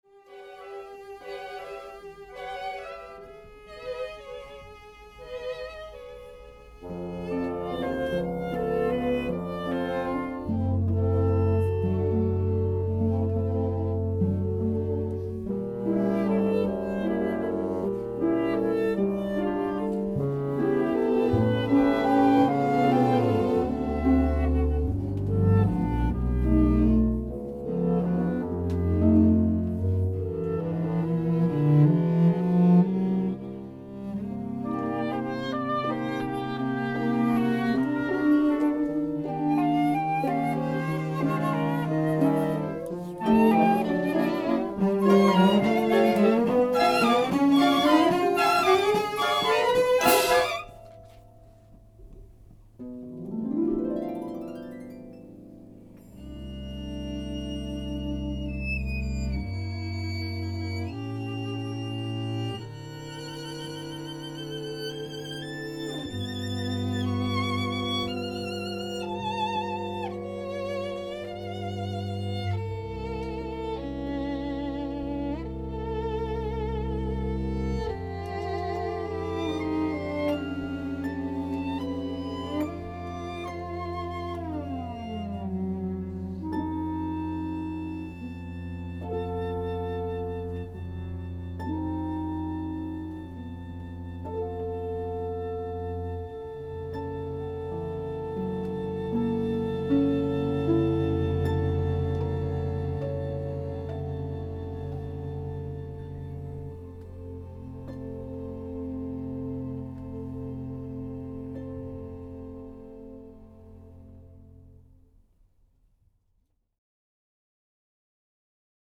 A group of guests sat outdoors as the ensemble played on the patio.
Arranged for chamber orchestra by Iain Farrington